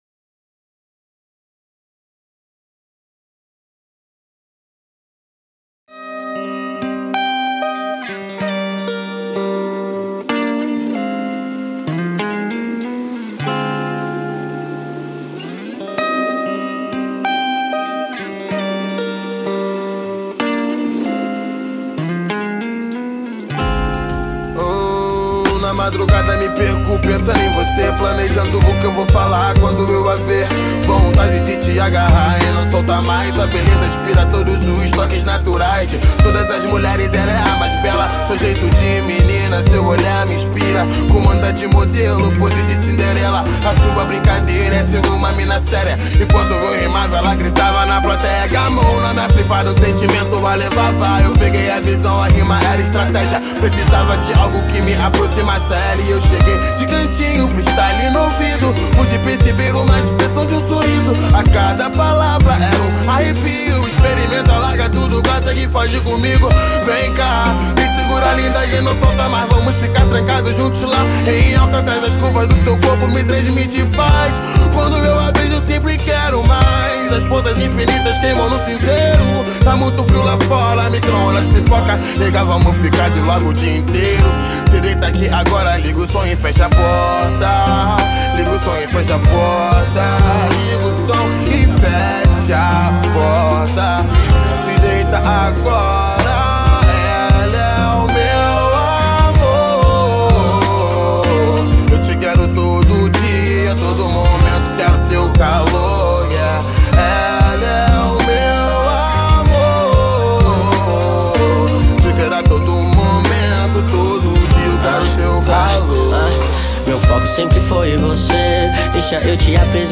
EstiloR&B